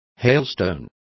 Complete with pronunciation of the translation of hailstones.